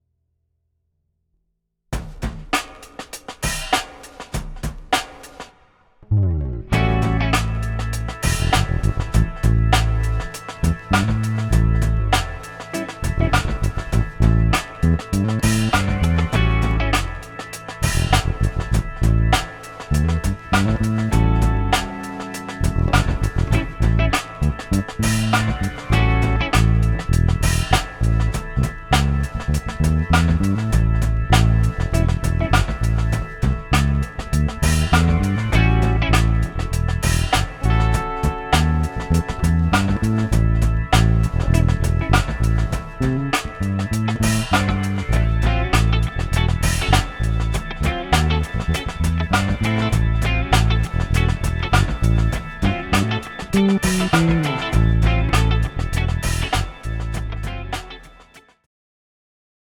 Ukázka 3 - krkový snímač, VTC na 70%, bass boost 40%, tomuhle nastavení říkám precloid